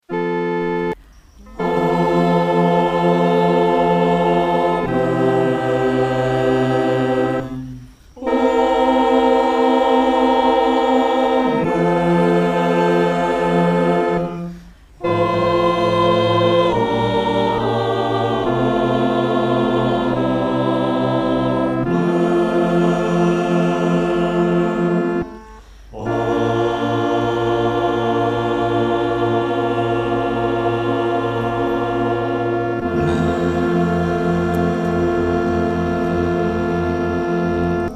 合唱
四声
本首圣诗由网上圣诗班录制